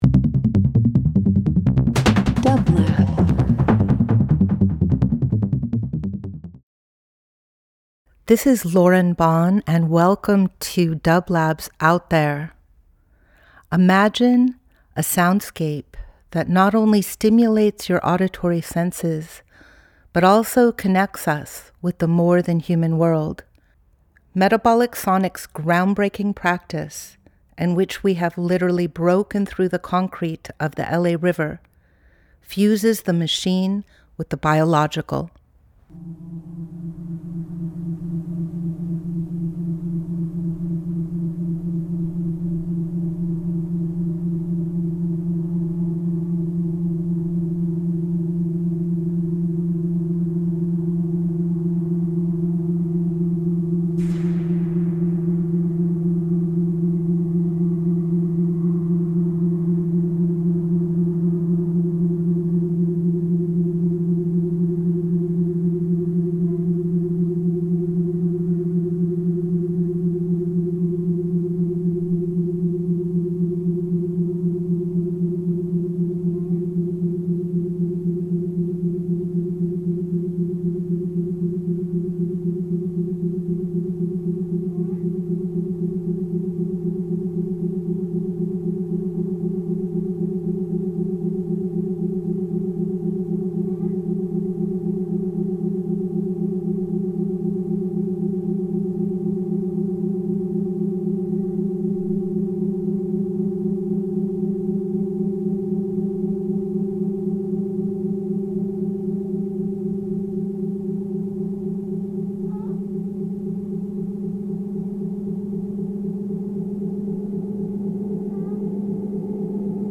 Ambient Field Recording Minimal Sound Art